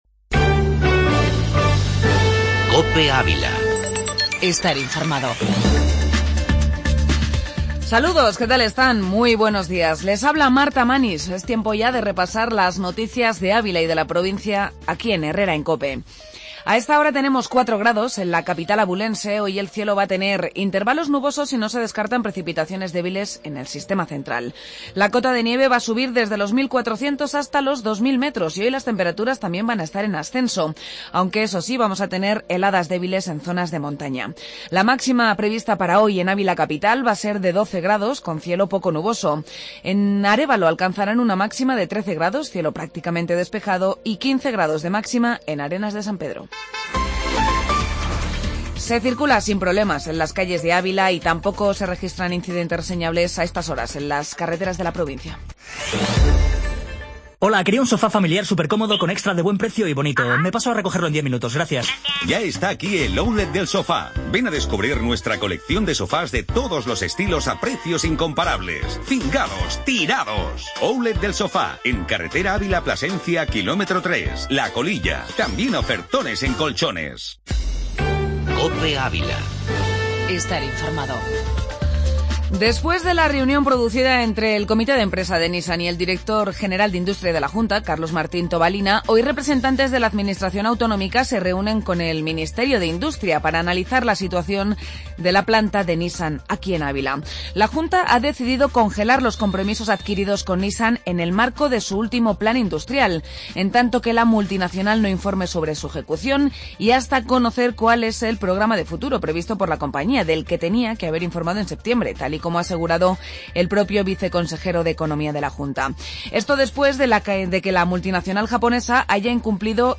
Informativo La Mañana en Ávila